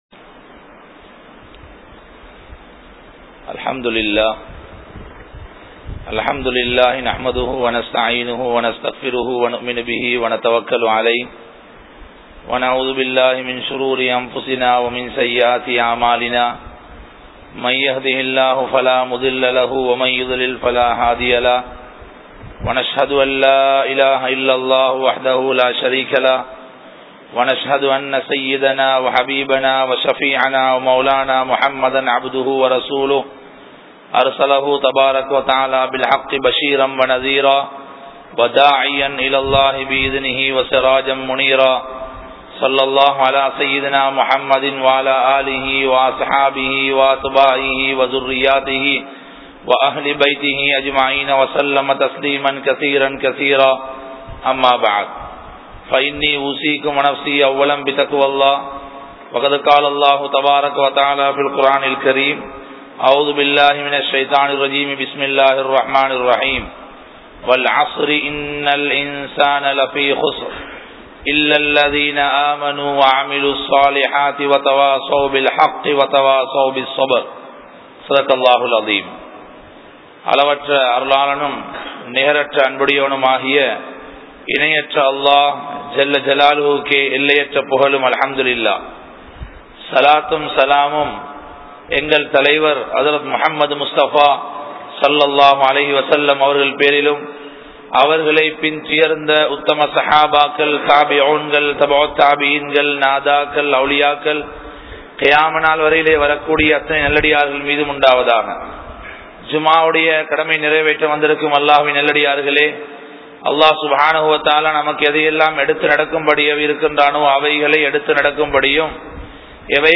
Kanampittya Masjithun Noor Jumua Masjith